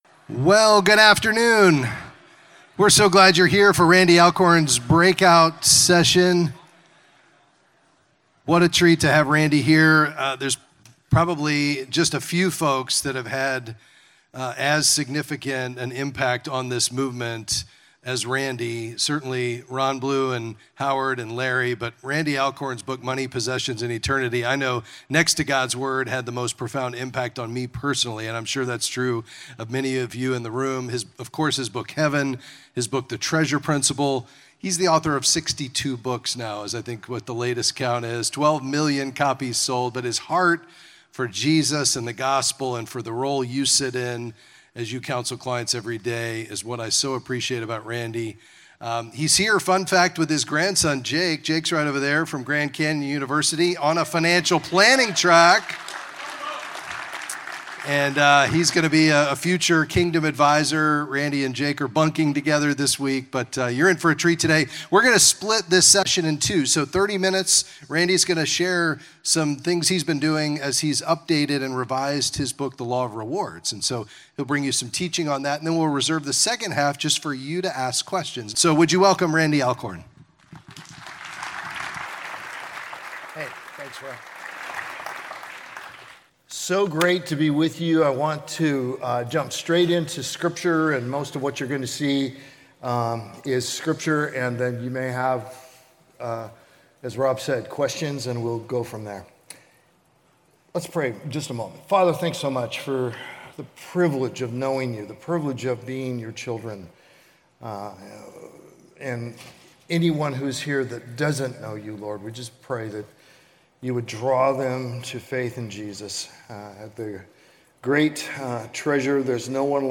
the_lor_with_audience_q&a-final_edited.mp3